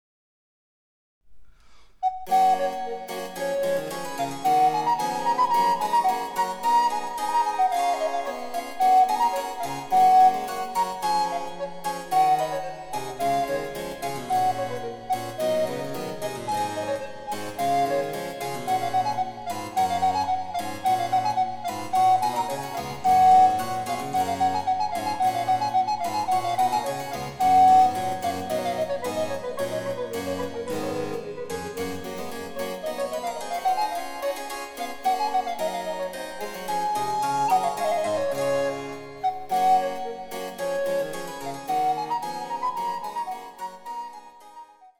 全体に晴朗ではれやかな感じの強い曲ですが、第３楽章は平行短調のホ短調で少し暗い表情をみせます。
■リコーダーによる演奏
バロックピッチによる）　　チェンバロ（電子楽器）